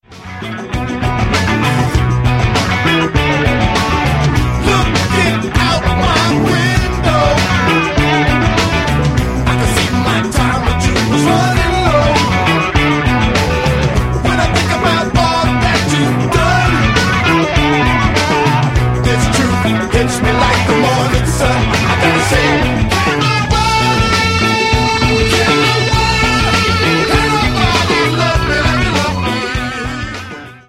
• Sachgebiet: Rock